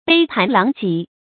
注音：ㄅㄟ ㄆㄢˊ ㄌㄤˊ ㄐㄧˊ
杯盤狼藉的讀法